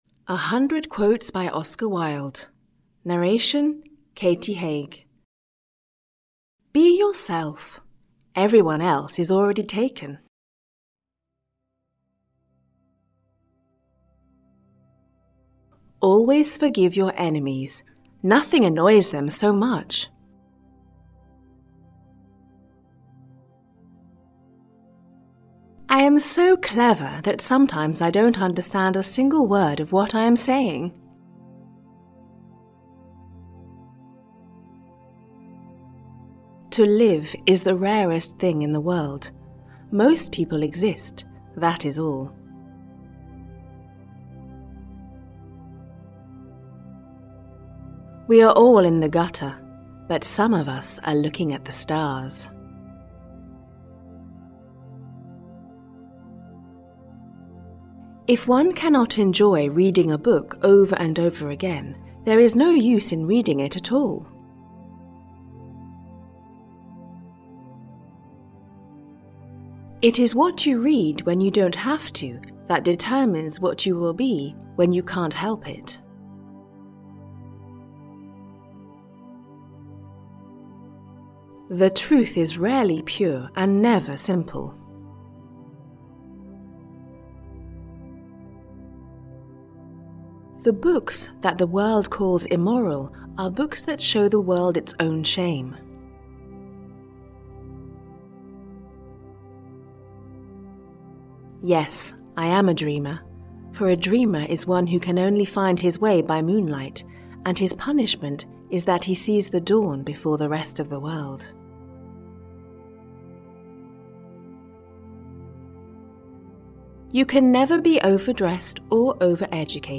Audiobook 100 Quotes by Oscar Wilde.
Ukázka z knihy